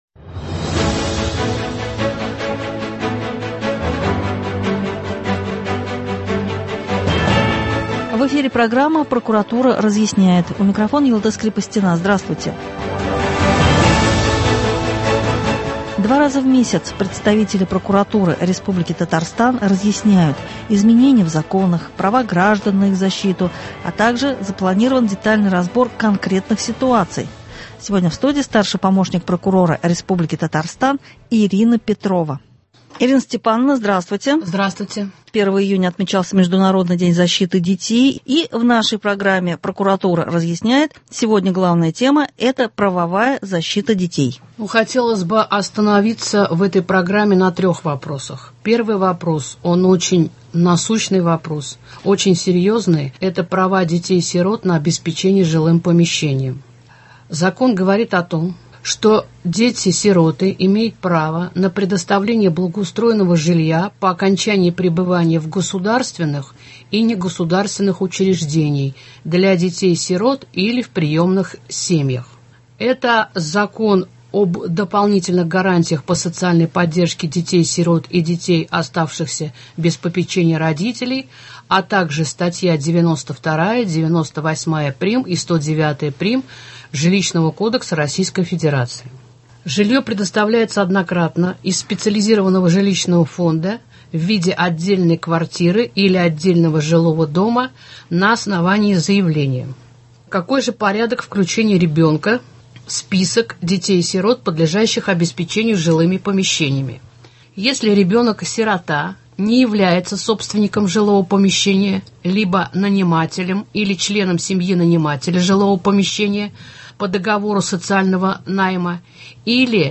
Два раза в месяц представители прокуратуры республики Татарстан разъясняют: изменения в законах, права граждан на их защиту, также запланирован детальный разбор конкретных ситуаций. В студии